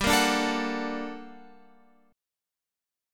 GmM9 chord {3 1 4 2 x 2} chord